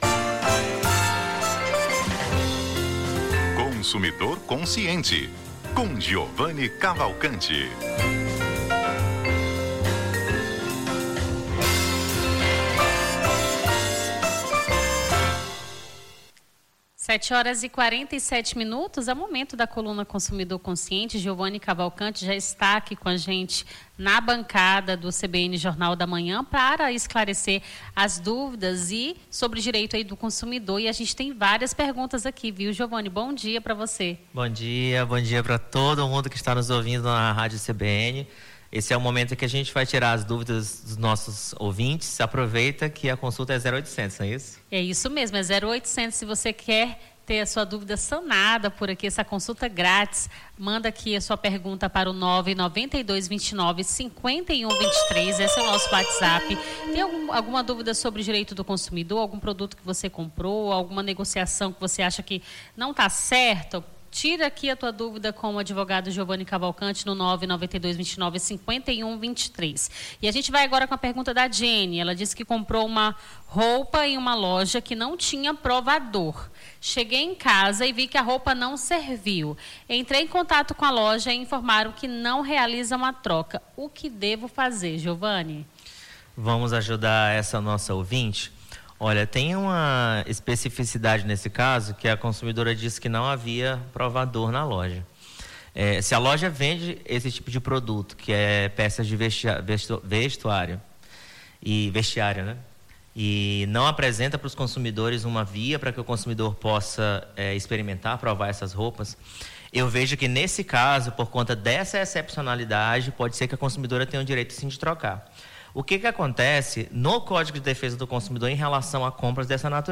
Consumidor Consciente: advogado tira dúvidas sobre direito do consumidor